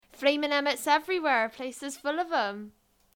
Examples of Cornish English
//ʹfleɪmɪŋ ʹeməts ʹevriweɻ/pleɪs ɪz fʊl əv ðem//